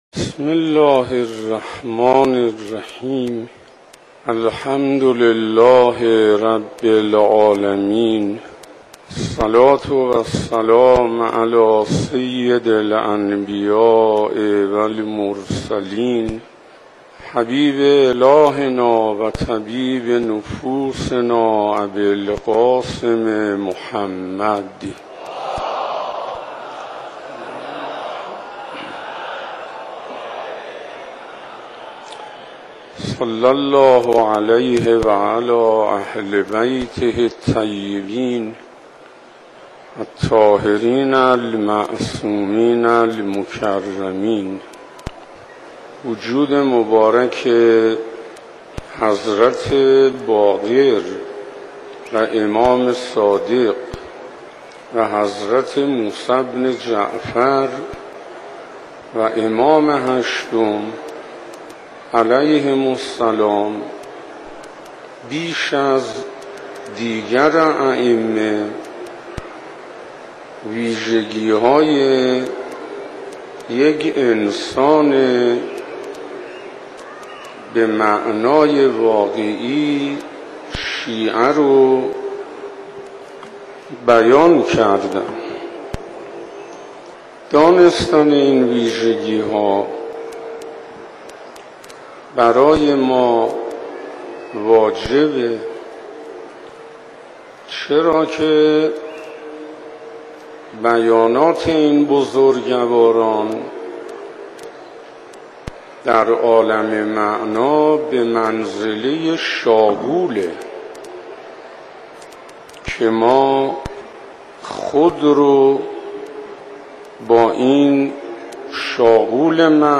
ویژگی های شیعیان سخنرانی حجت الاسلام انصاریان